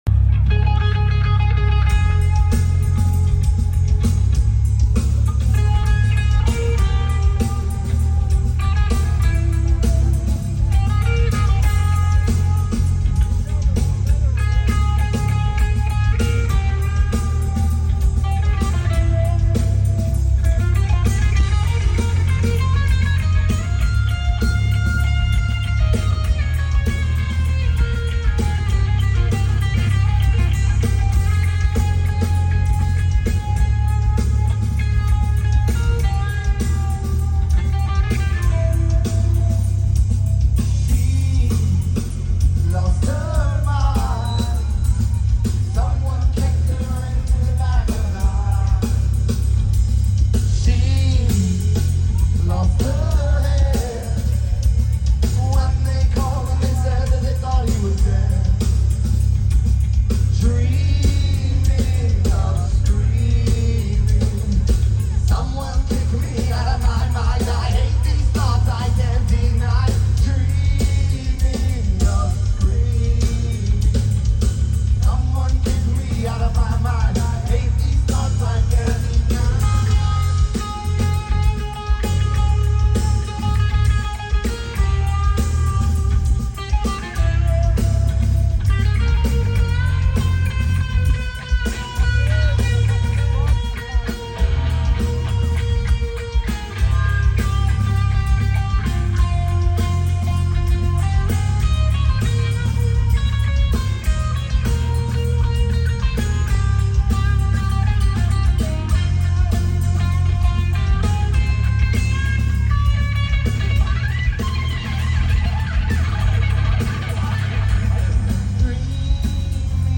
at MetLife Stadium on 8/27/25